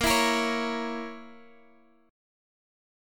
Bbdim chord